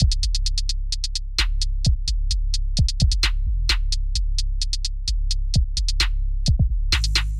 简单的陷阱节拍，深沉的低音
描述：陷阱咄咄逼人，120 bpm
标签： 女巫 808 低音 循环 陷阱 击败
声道立体声